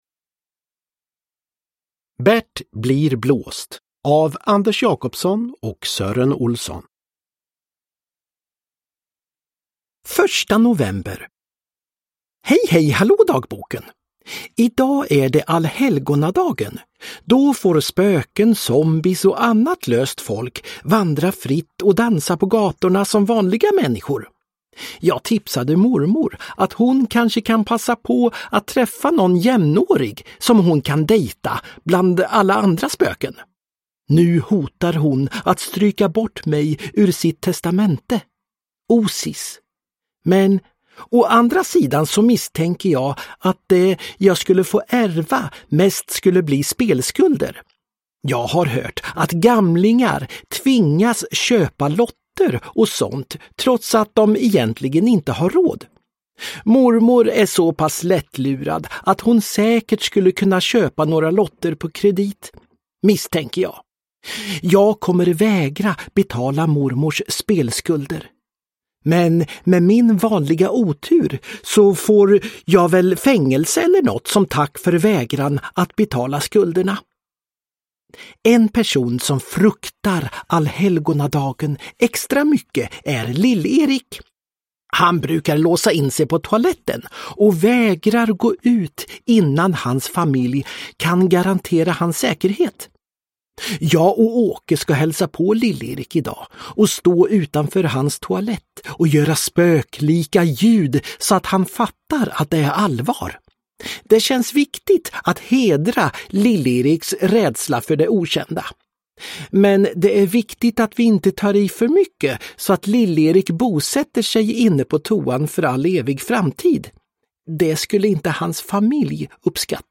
Bert blir blåst – Ljudbok
Uppläsare: Sören Olsson